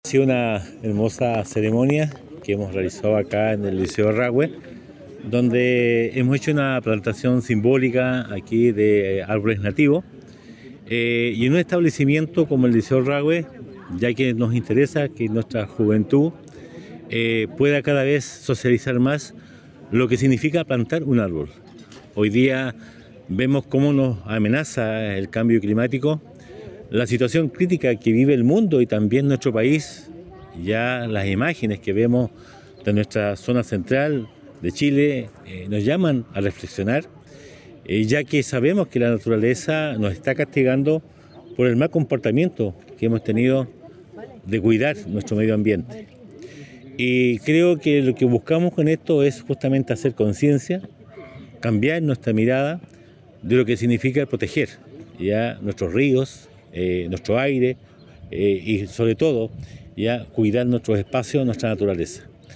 El Alcalde de Osorno, Emeterio Carrillo señaló que esta ceremonia fue muy importante para que la juventud pueda tomar conciencia acerca de la naturaleza y reflexionar en torno a su cuidado.